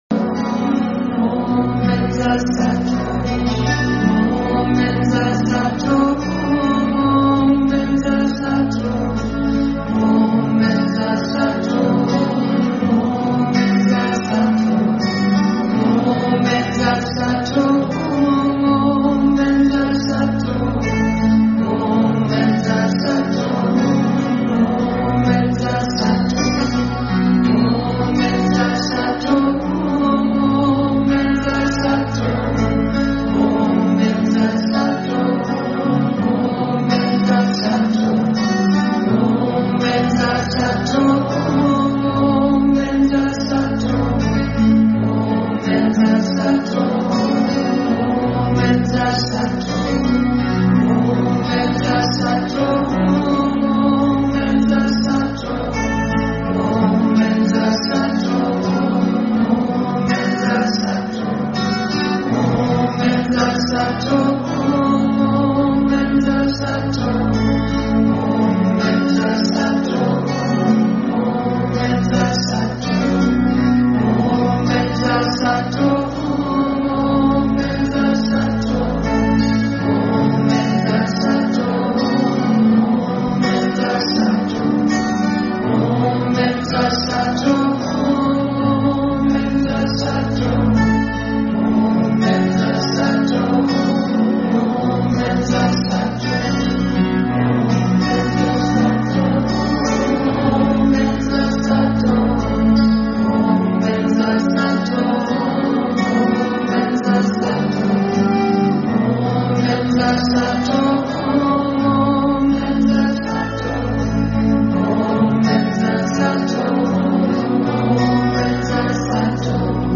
در تلفظ تبتی، Vajra → Benza تلفظ می‌شود.